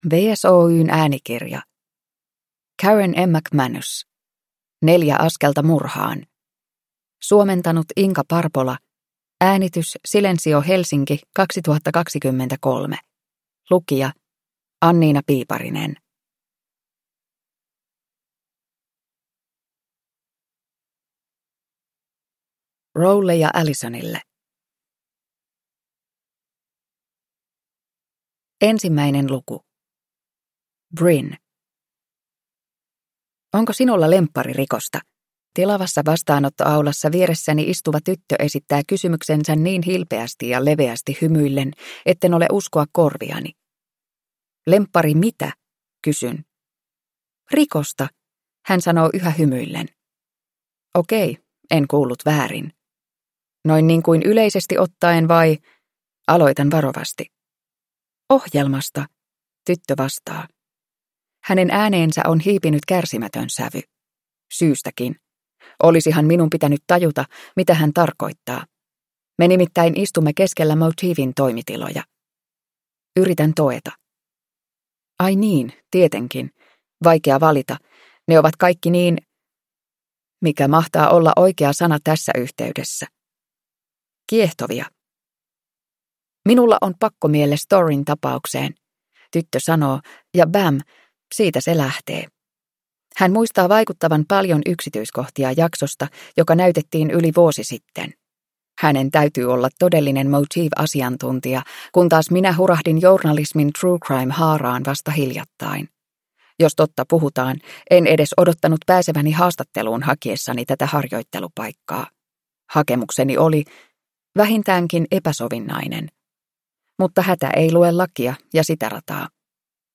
Neljä askelta murhaan – Ljudbok